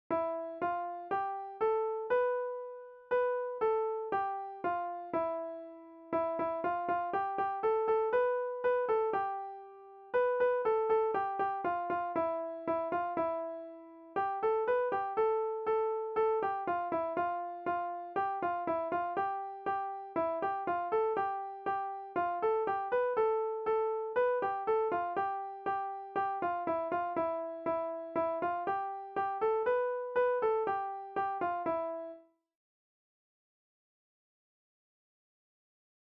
Practica o seguinte exercicio coa frauta. Escoita o audio para ter unha referencia.
exercicio_3_fa_mi.mp3